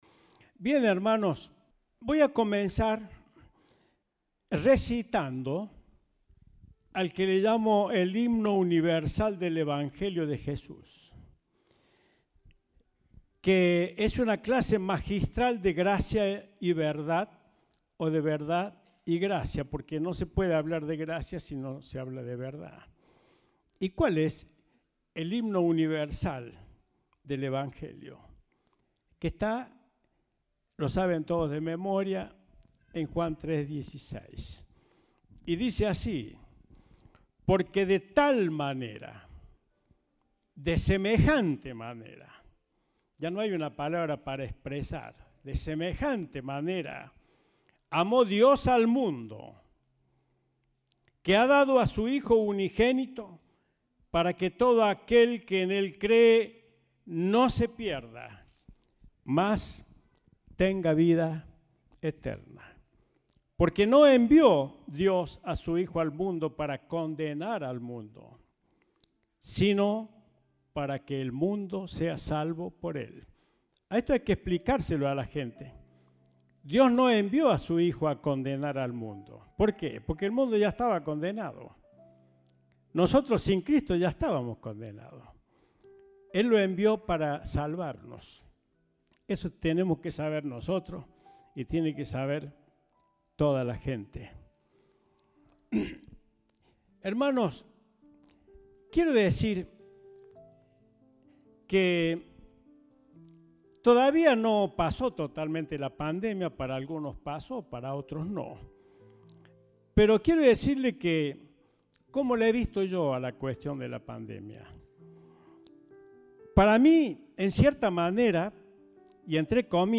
Compartimos el mensaje del Domingo 18 de Septiembre de 2022.